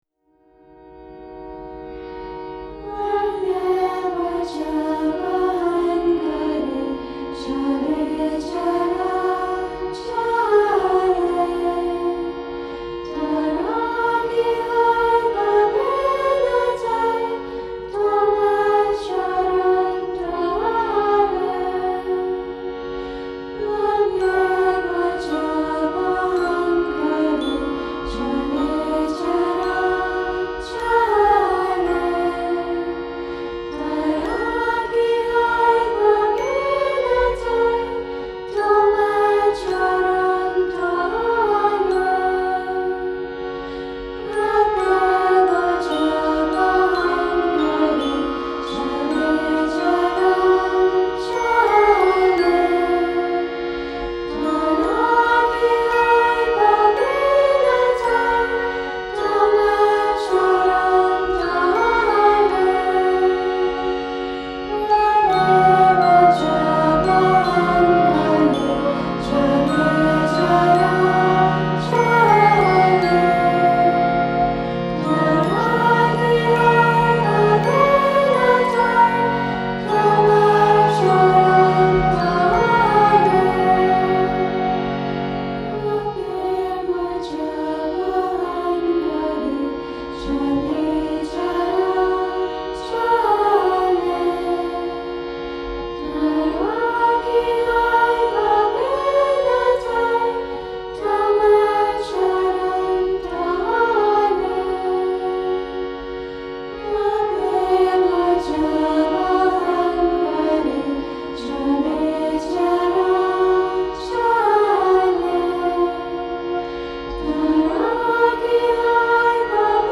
haunting and soulful performance
very conducive for meditation
female vocal & instrumental ensemble
harmonium & glockenspiel, flute and violin